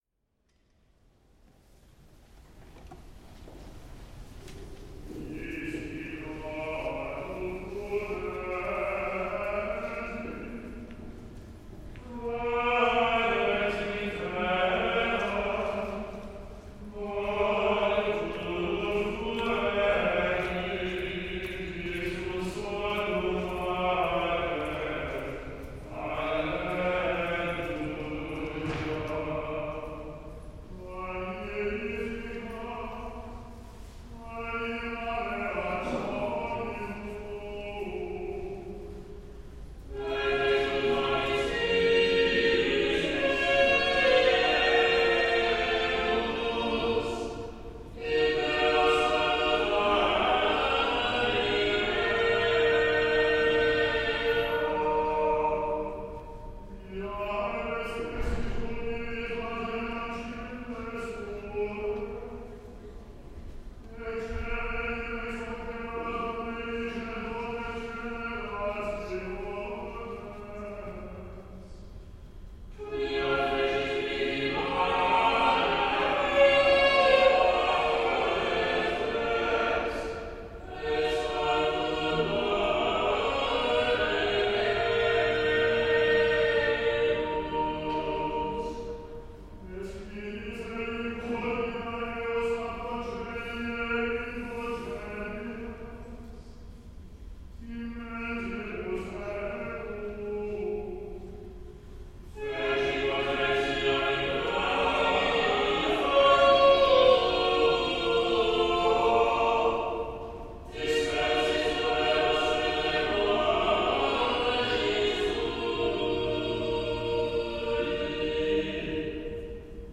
vespers-westminster-cathedral.mp3